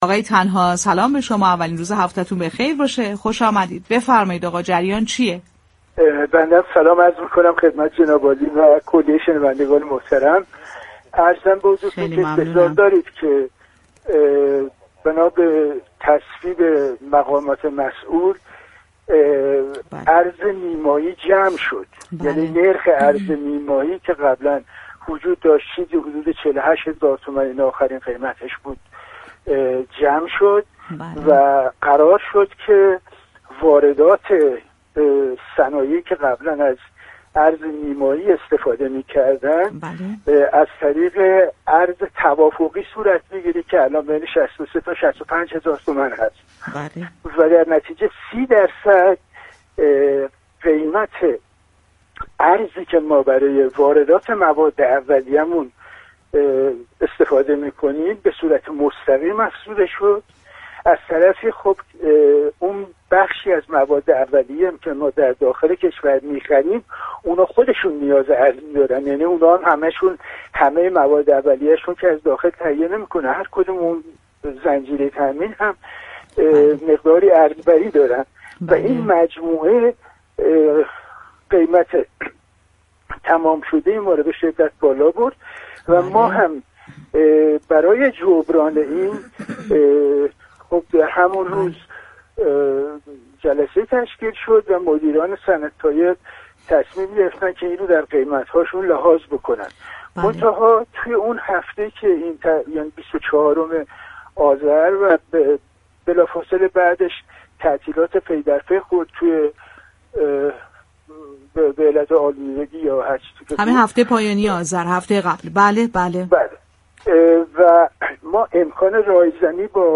در گفت و گو با رادیو تهران